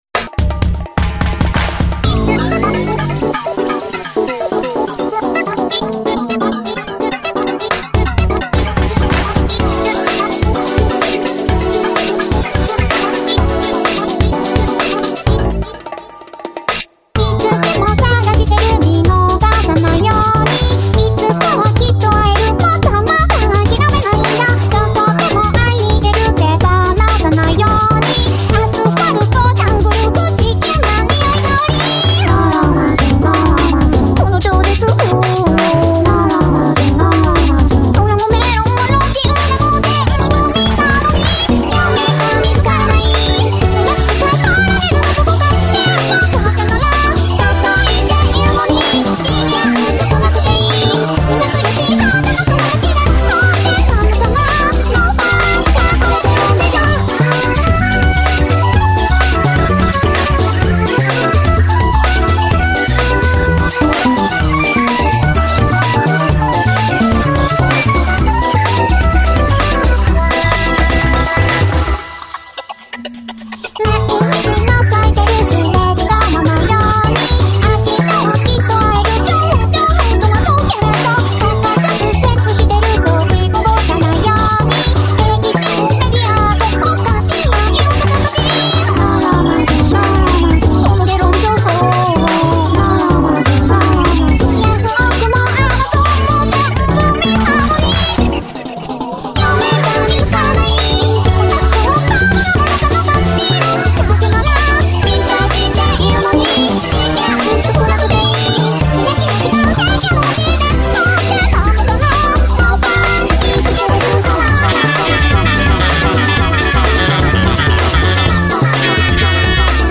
denpa track
bizarrely funky, almost recent-flying-lotus-y beautiful mess